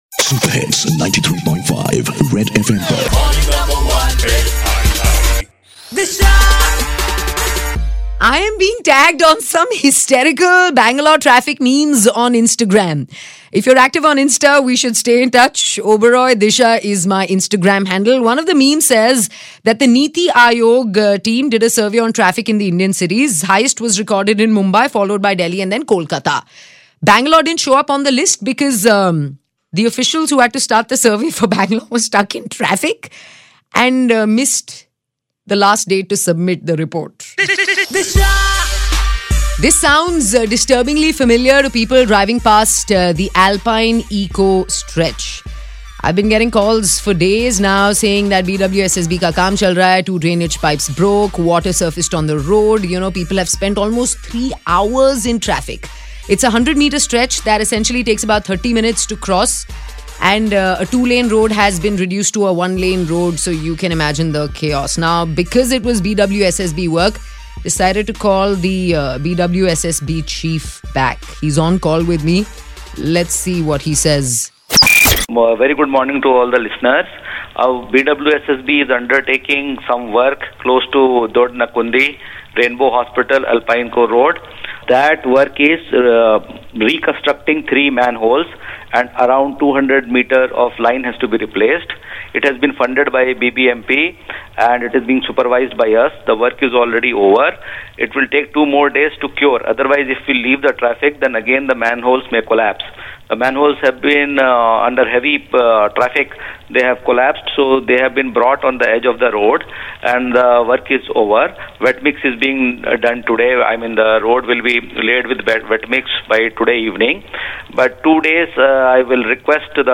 BWSSB Chairman Mr Tushar Kanti is on air- Requests Bangaloreans to be paitent